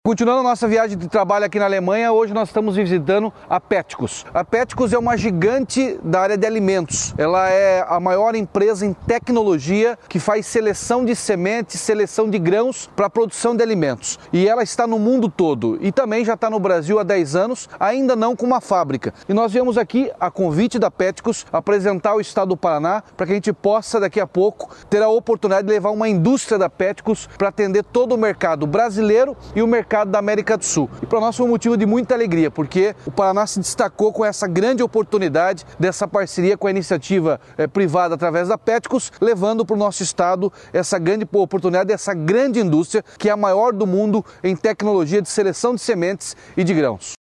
Sonora do governador Ratinho Junior sobre a negociação da instalação no Paraná de uma fábrica da Petkus, da Alemanha